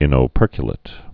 (ĭnō-pûrkyə-lĭt)